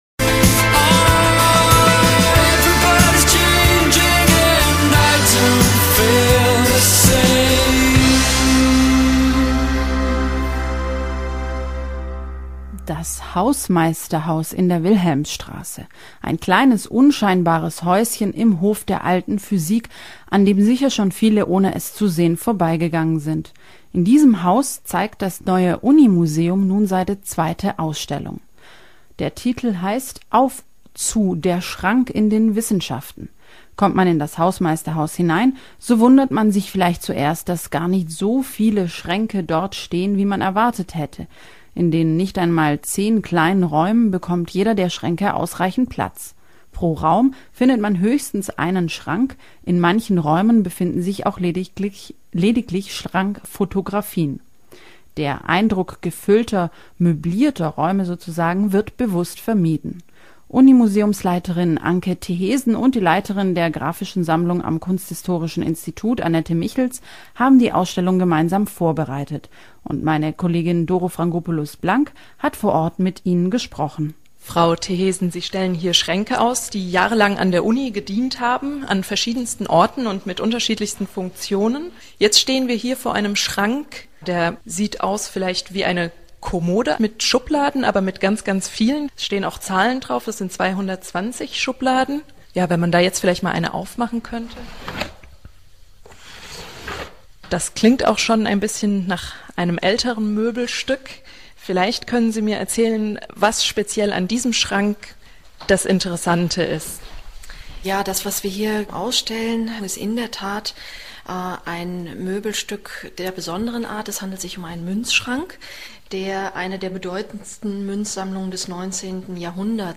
Uniradio-Beitrag.mp3